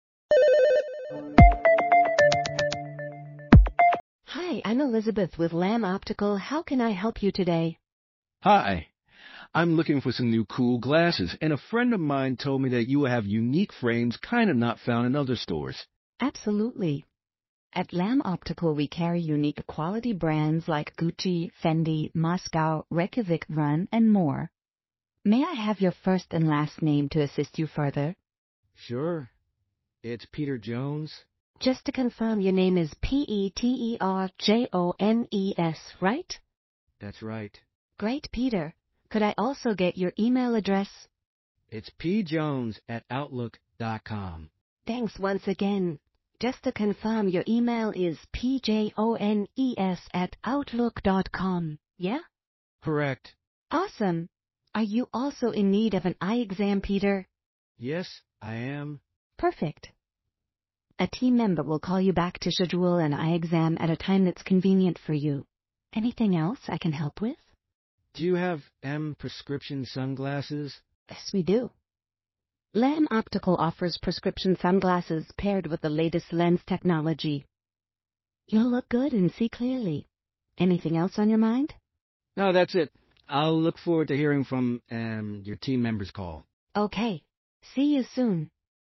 Escuche a continuación una demostración de voz AI personalizada
Recepcionista de Inteligencia Artificial